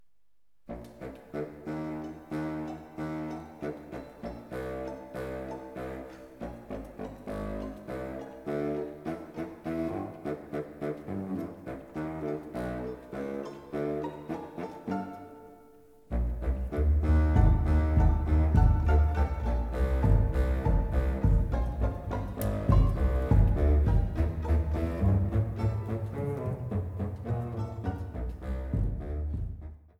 Alla marcia